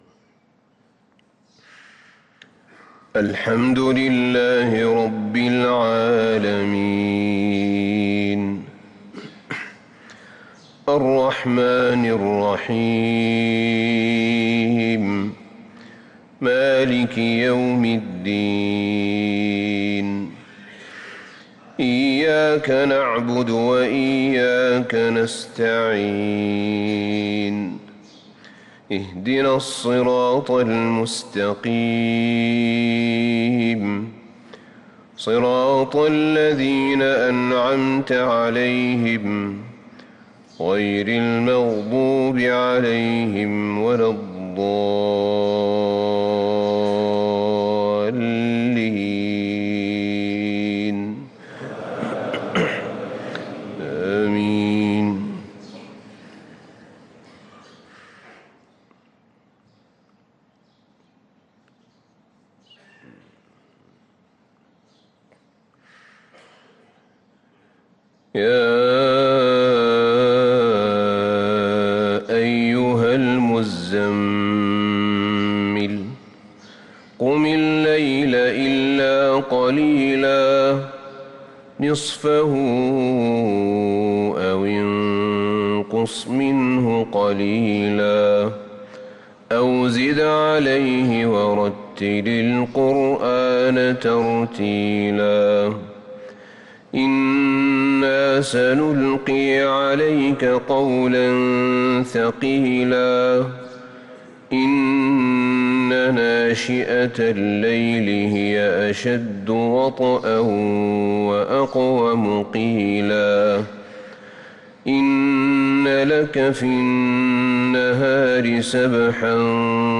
صلاة الفجر للقارئ أحمد بن طالب حميد 16 ربيع الآخر 1445 هـ